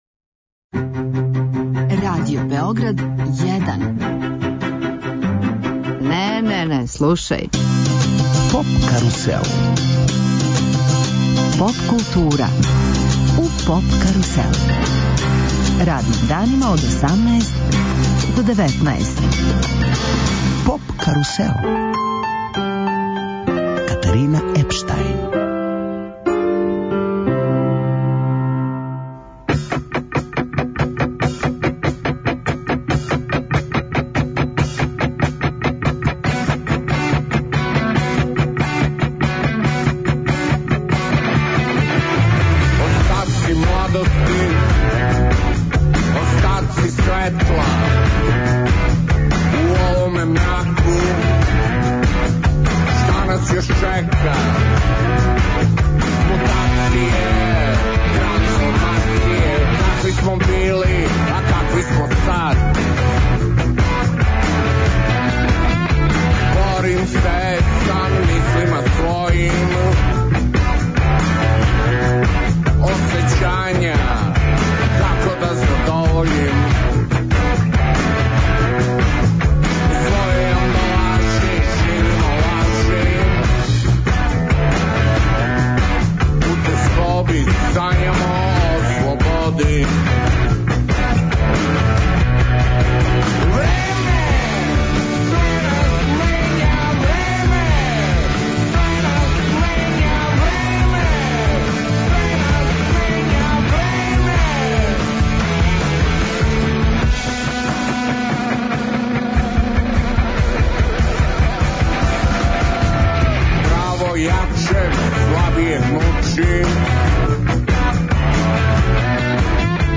Гост емисије је Зоран Костић Цане, поводом објављивања албума састава Шкртице.